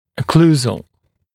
[ə’kluːzəl] [-səl][э’клу:зэл] [-сэл]окклюзионный, относящийся к прикусу